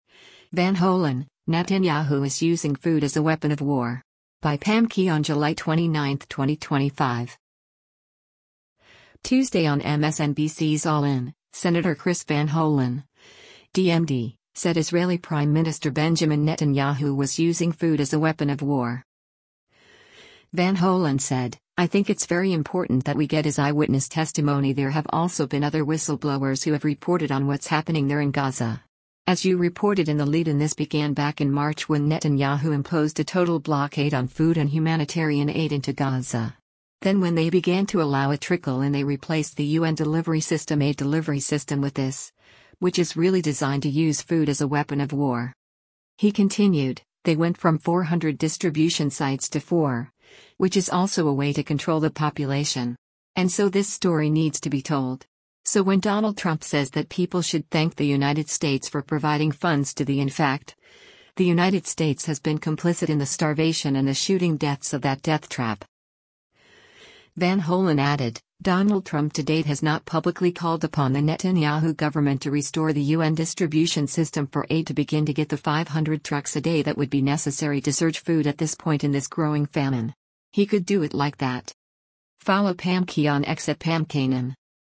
Tuesday on MSNBC’s “All In,” Sen. Chris Van Hollen (D-MD) said Israeli Prime Minister Benjamin Netanyahu was using “food as a weapon of war.”